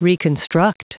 Prononciation du mot reconstruct en anglais (fichier audio)
Prononciation du mot : reconstruct